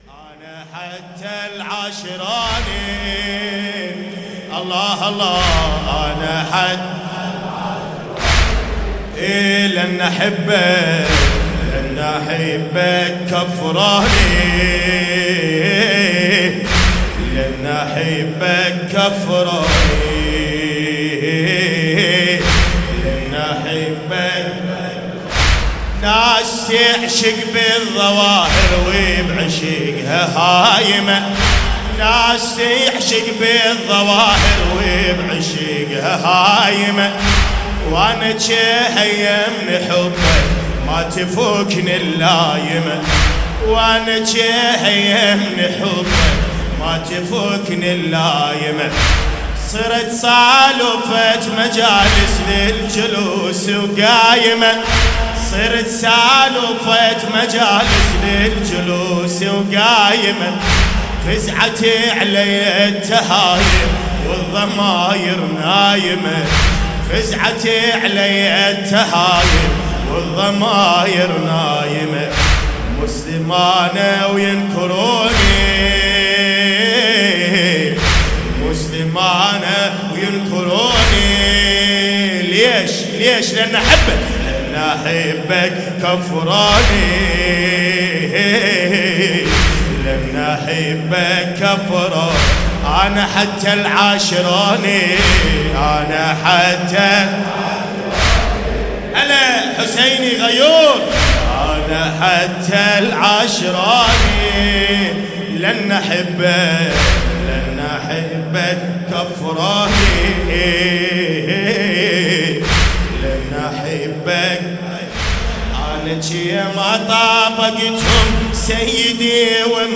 محمد معتمدی کربلایی
لیالي محرم الحرام 1440 محمد معتمدي الکربلائي الشاعر هادي الزینبي حسینیة سیدالشهداء لبنان بیروت
محرم 98 سینه زنی مداحی عربی امام حسین